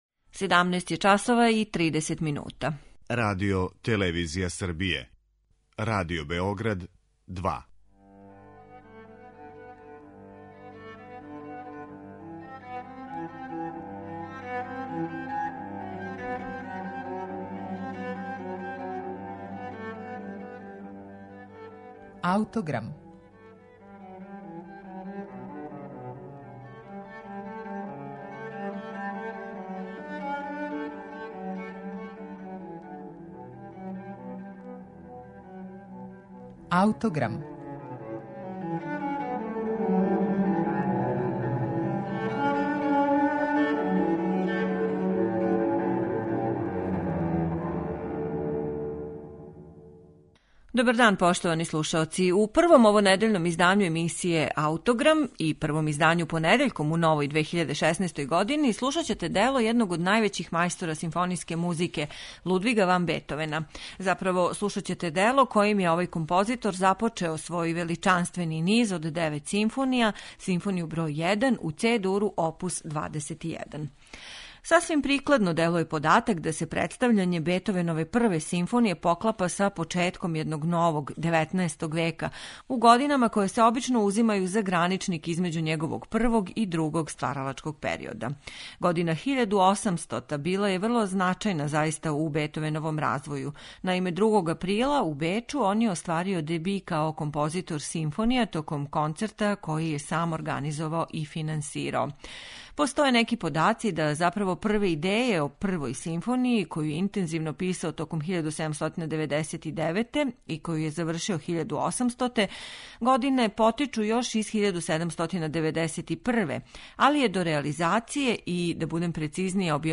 у Цe-Дуру